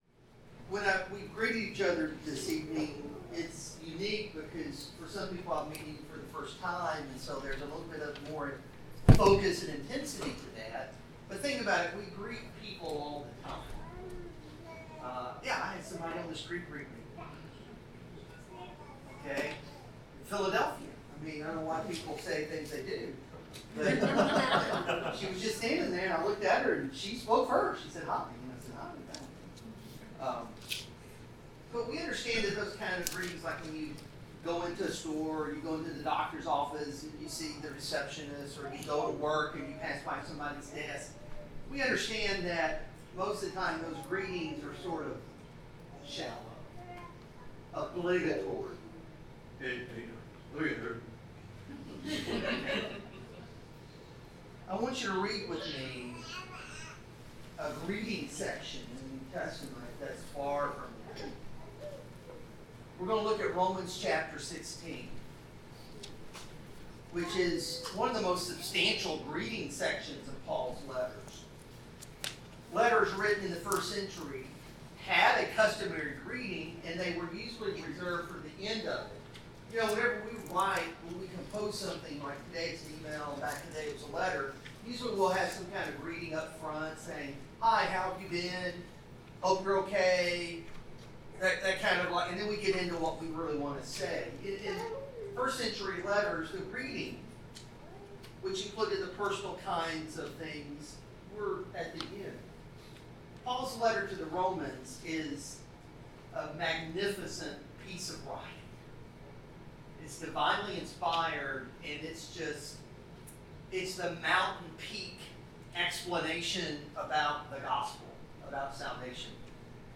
Passage: Romans 16:1-16 Service Type: Sermon